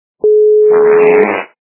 » Звуки » Смешные » Попа - Неприличный звук
При прослушивании Попа - Неприличный звук качество понижено и присутствуют гудки.
Звук Попа - Неприличный звук